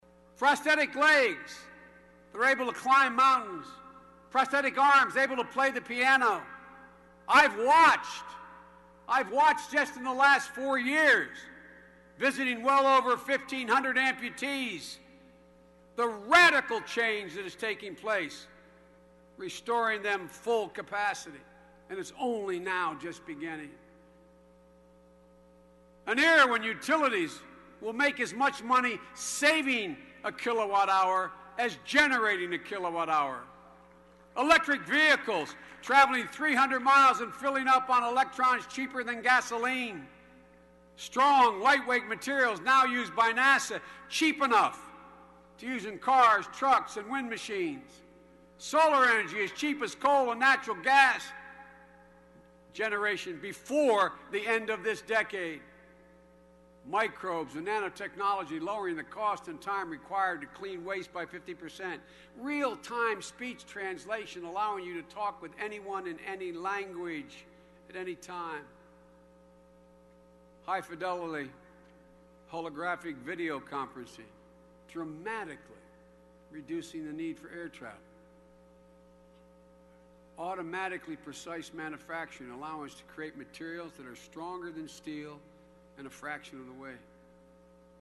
公众人物毕业演讲第413期:拜登2013宾夕法尼亚大学(11) 听力文件下载—在线英语听力室